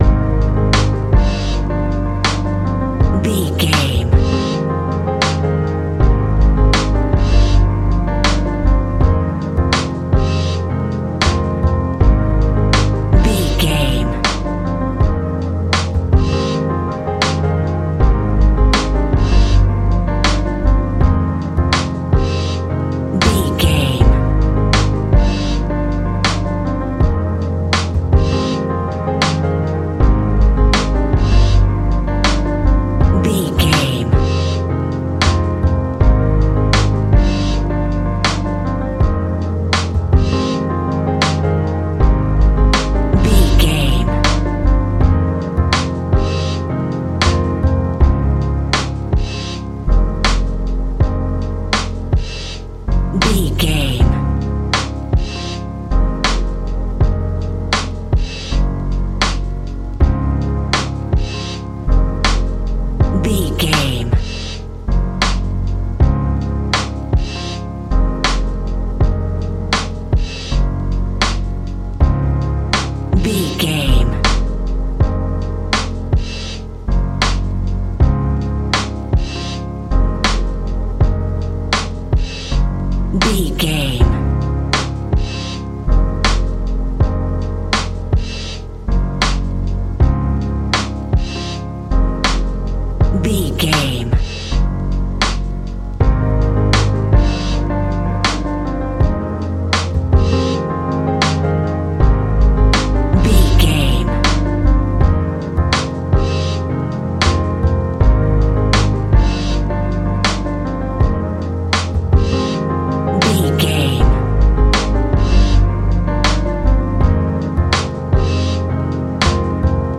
Ionian/Major
F♯
chilled
laid back
Lounge
sparse
new age
chilled electronica
ambient
atmospheric
instrumentals